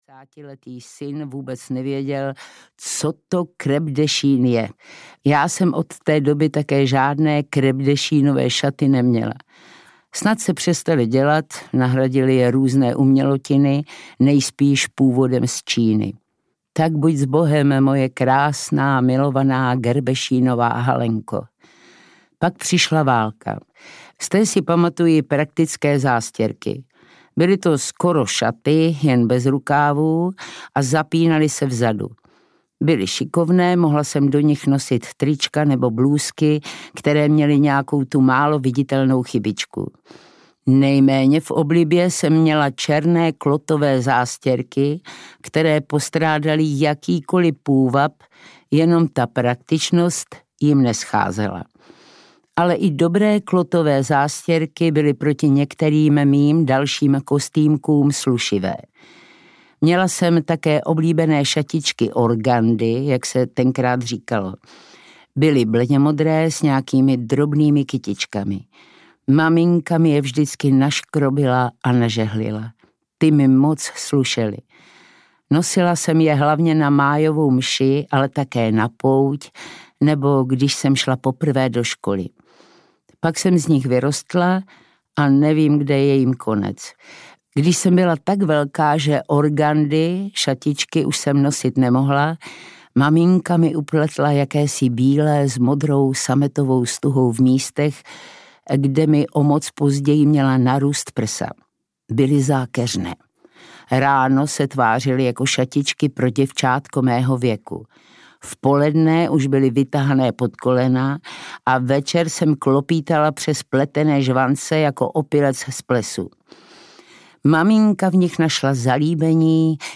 Gerbešín a hezouni audiokniha
Ukázka z knihy
• InterpretPavla Tomicová